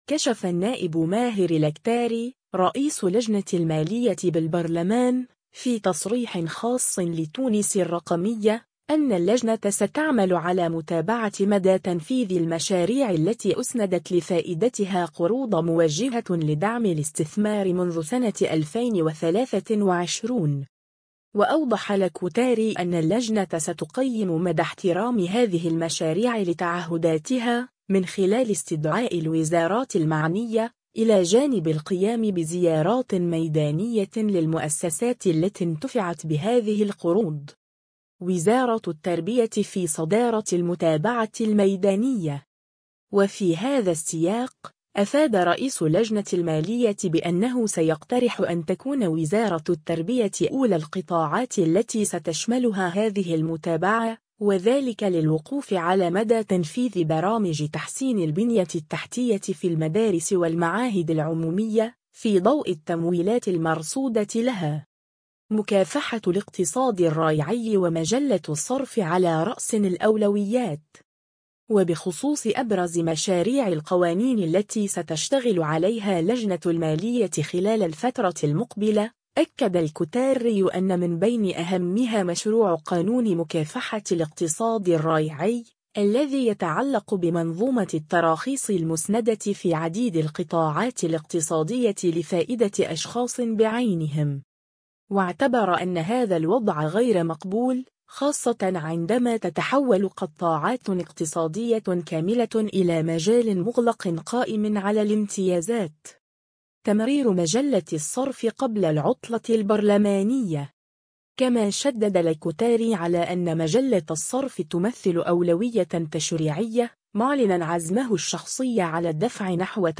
كشف النائب ماهر الكتاري، رئيس لجنة المالية بالبرلمان، في تصريح خاص لـ”تونس الرقمية”، أن اللجنة ستعمل على متابعة مدى تنفيذ المشاريع التي أُسندت لفائدتها قروض موجّهة لدعم الاستثمار منذ سنة 2023.